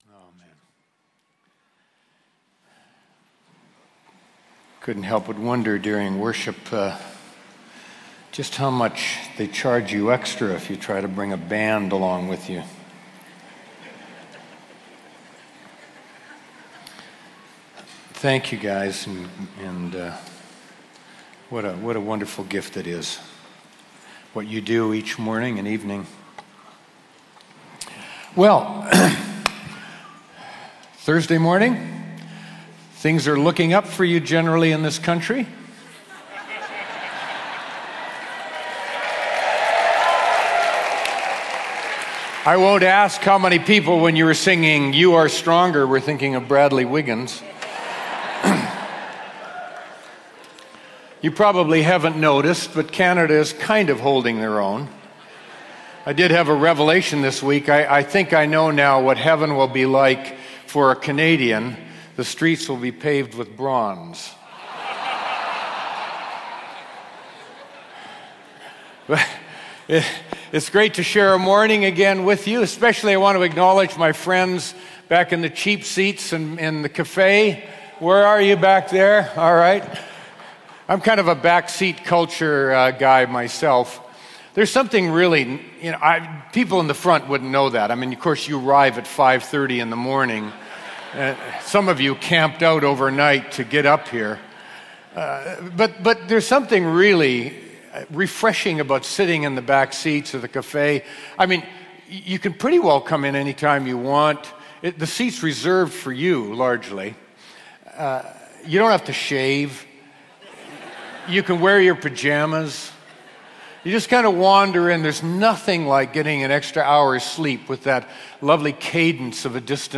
Teaching from New Wine Christian Conference – for all to share.